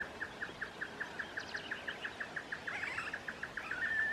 Great Antshrike (Taraba major)
Life Stage: Adult
Location or protected area: Miramar
Condition: Wild
Certainty: Recorded vocal